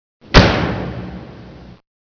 B_TOCTOC.mp3